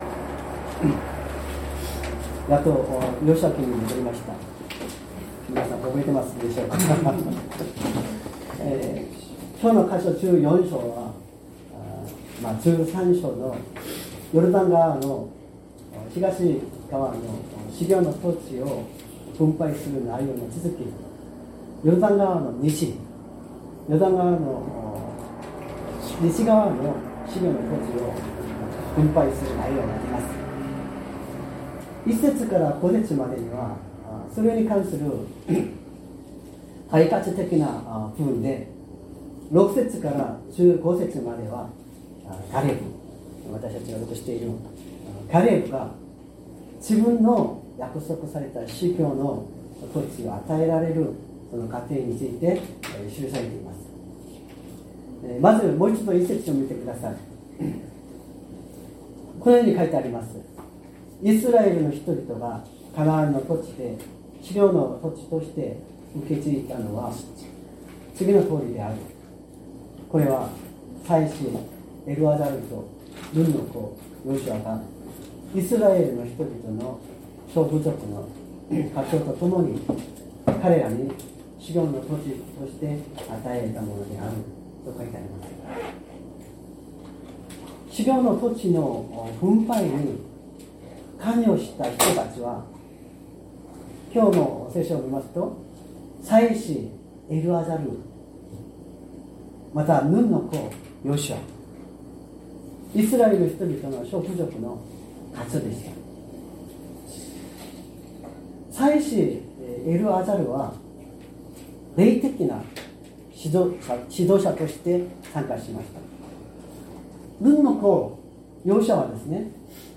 2025年01月12日朝の礼拝「この山地をわたしにください」善通寺教会
善通寺教会。説教アーカイブ 2025年01月12日朝の礼拝「この山地をわたしにください」
音声ファイル 礼拝説教を録音した音声ファイルを公開しています。